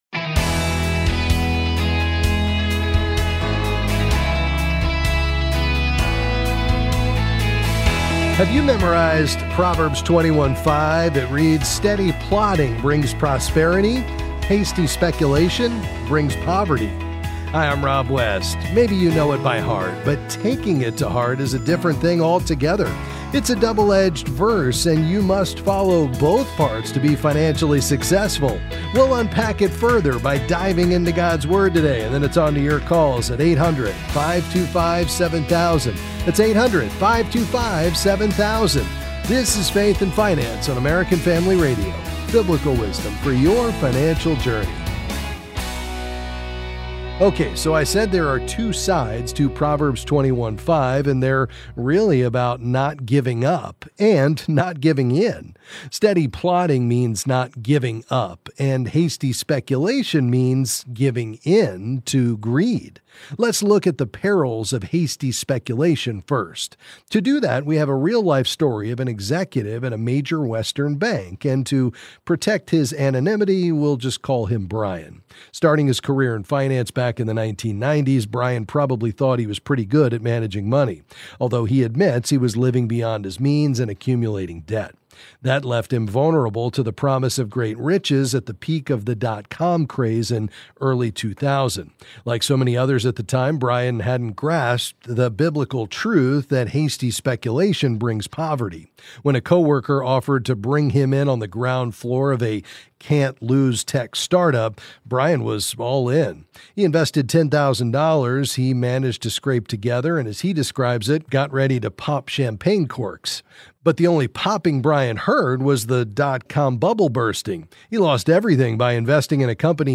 Then he’ll answer your calls and financial questions.